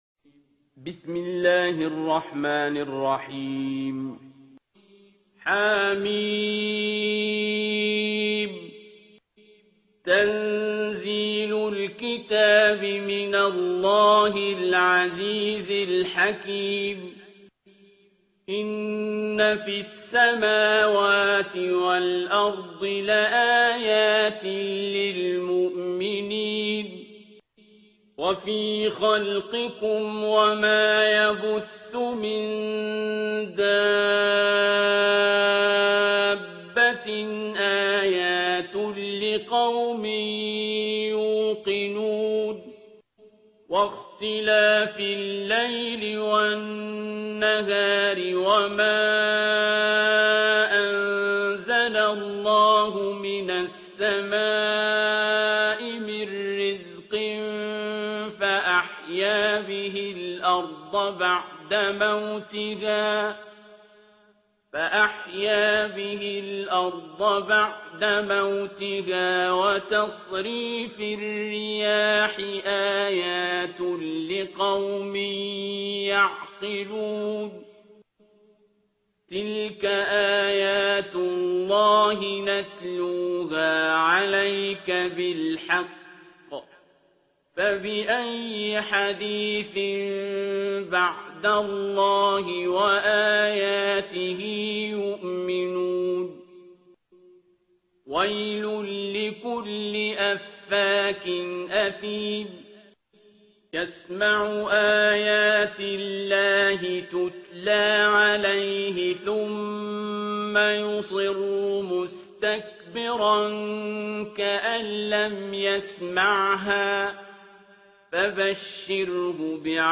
ترتیل سوره جاثیه با صدای عبدالباسط عبدالصمد
045-Abdul-Basit-Surah-Al-Jathiya.mp3